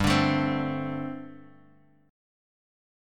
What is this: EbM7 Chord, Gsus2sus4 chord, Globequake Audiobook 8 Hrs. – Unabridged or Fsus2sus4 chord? Gsus2sus4 chord